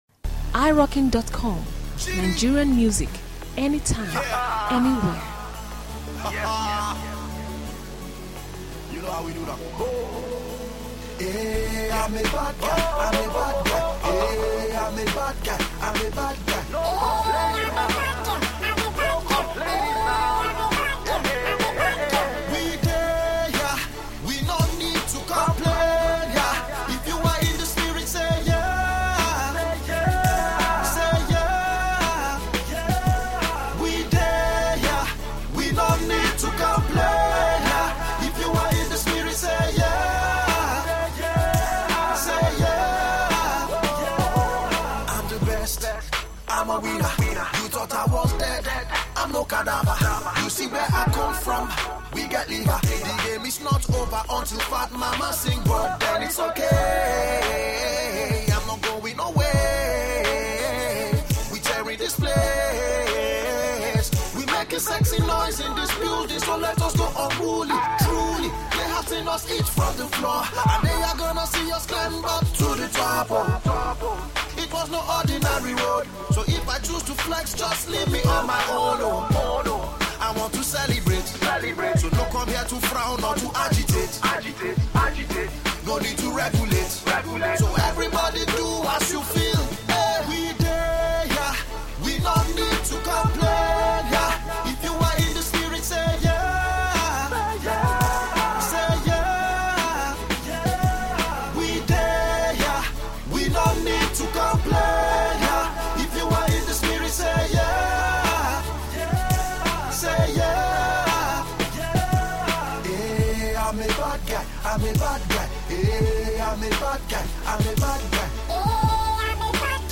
an upbeat track
keeps us dancing